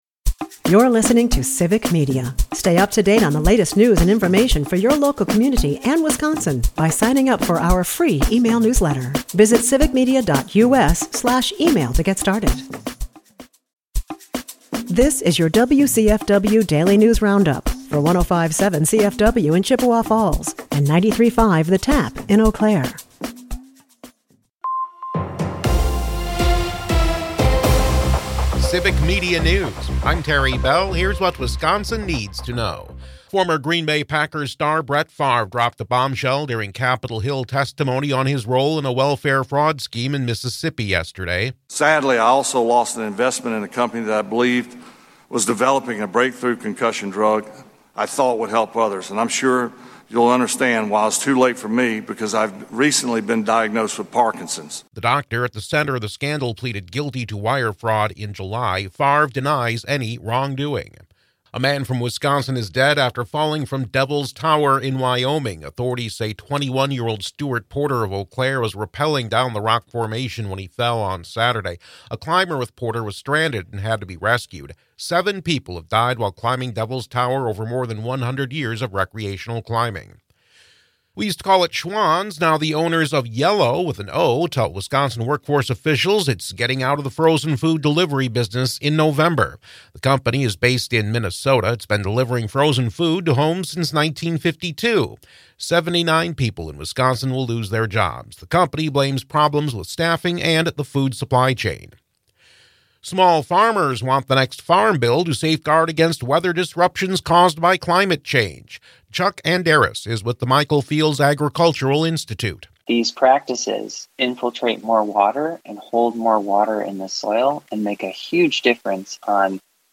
WCFW Wednesday News Roundup - WCFW News